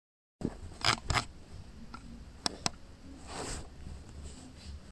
13-Прокрутил-и-нажал-на-колёсико-мышки
• Категория: Мышь компьютера
• Качество: Высокое